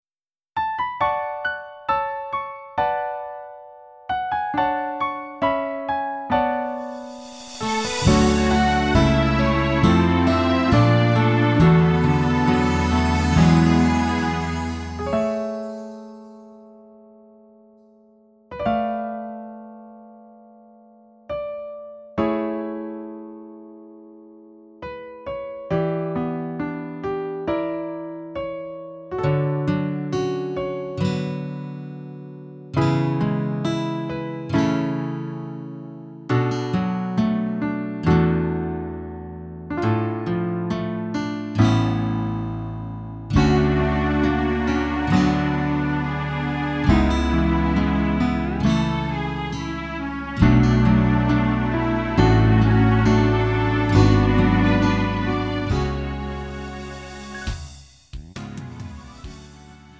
음정 -1키 3:57
장르 가요 구분 Lite MR
Lite MR은 저렴한 가격에 간단한 연습이나 취미용으로 활용할 수 있는 가벼운 반주입니다.